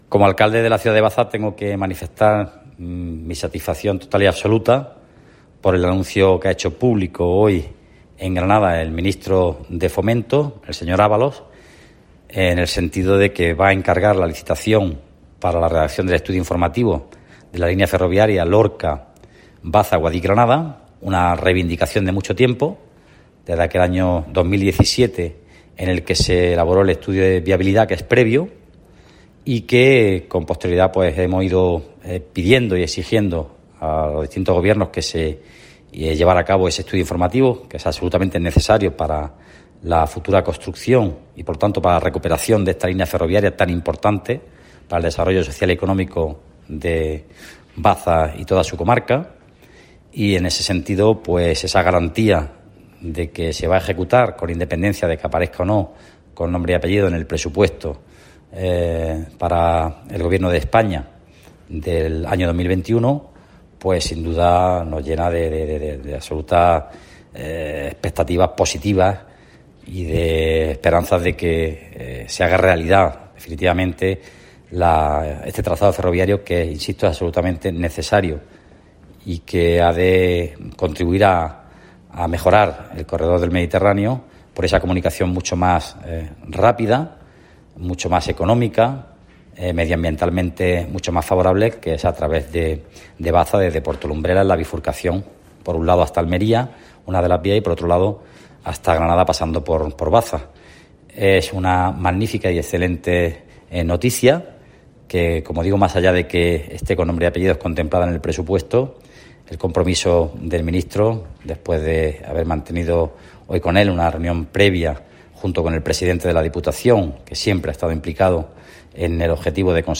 A continuación ofrecemos una grabación de audio en la que el alcalde bastetano hace referencia a este asunto, en el que destaca también la labor de la Asociación Amigos del Ferrocarril Comarca de Baza: